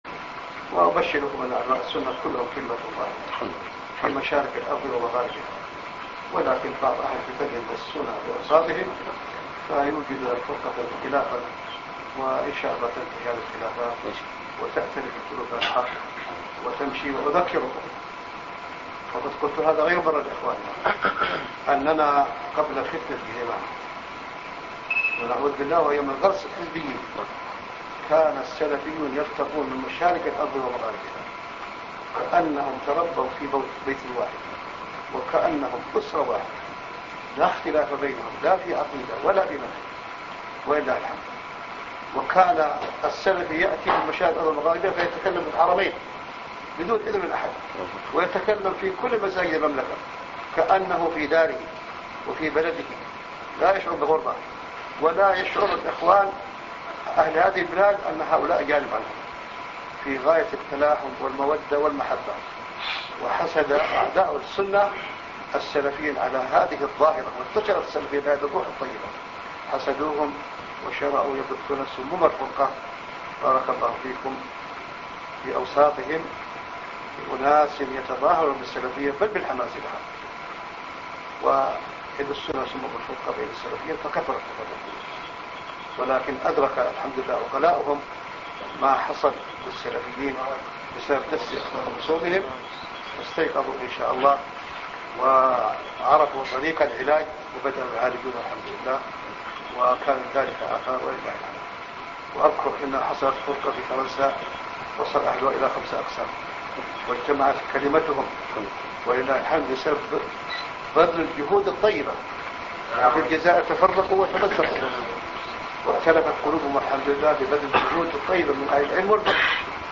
من مواعظ أهل العلم القسم العلمي